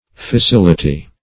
Fissility \Fis*sil"i*ty\